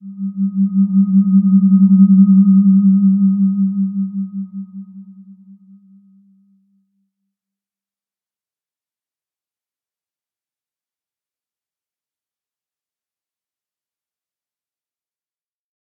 Slow-Distant-Chime-G3-p.wav